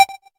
blip2.ogg